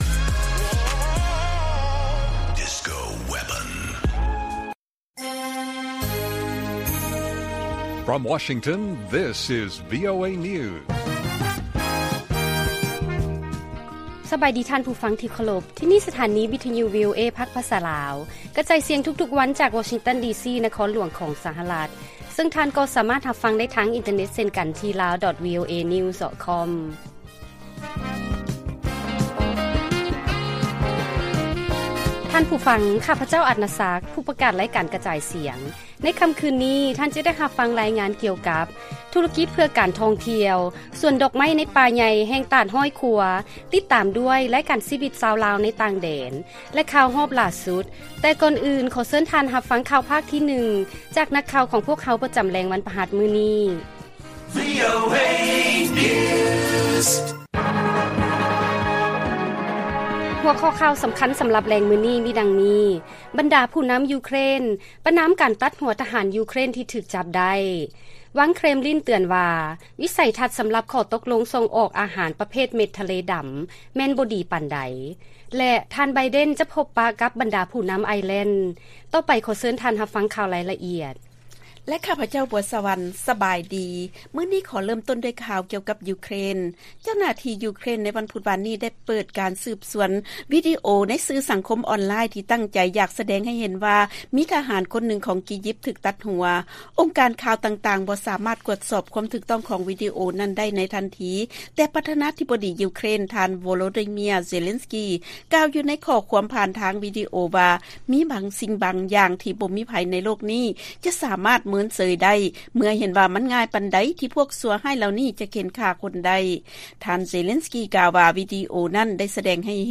ລາຍການກະຈາຍສຽງຂອງວີໂອເອ ລາວ: ບັນດາຜູ້ນຳຢູເຄຣນ ປະນາມການຕັດຫົວທະຫານຢູເຄຣນທີ່ຖືກຈັບໄດ້